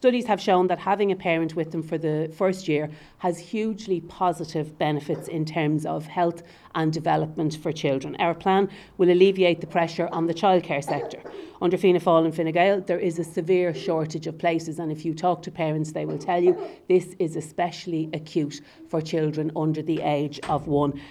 Sinn Féin spokesperson on Workers' Rights, Louise O'Reilly says it would take pressure off the childcare sector.